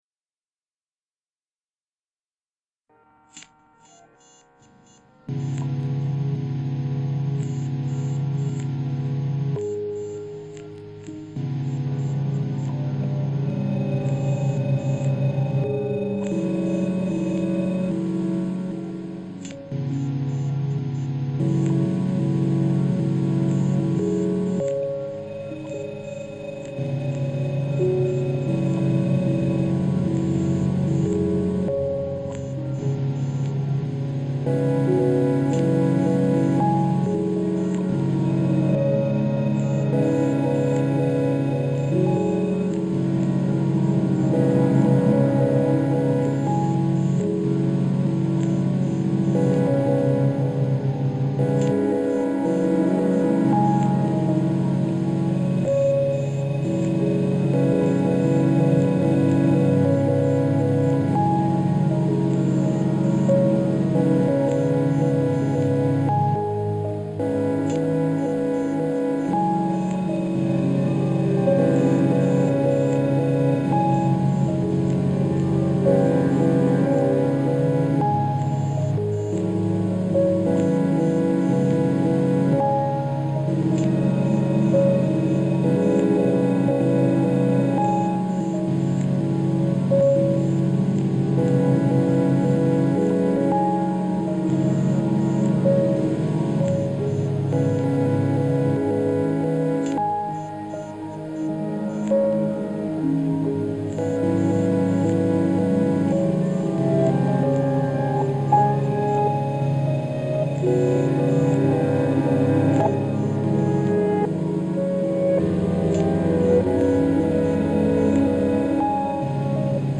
abstracto pero rico en texturas.